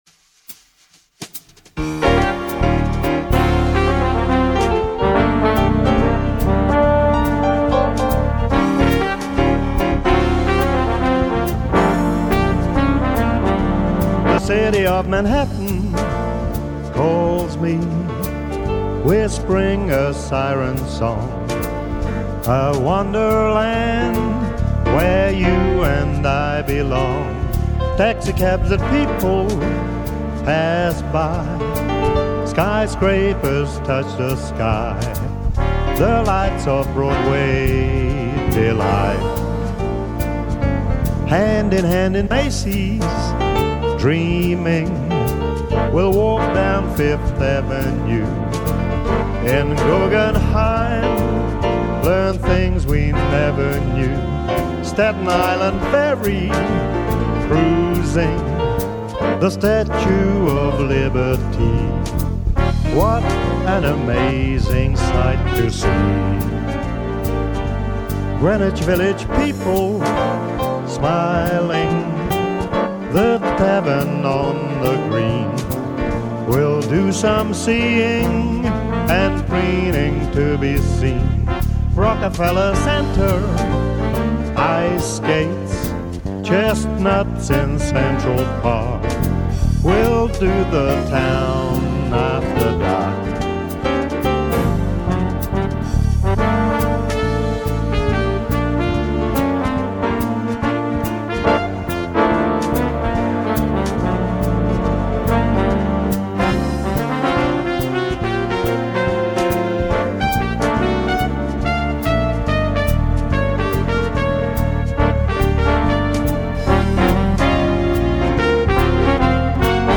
Big Band sound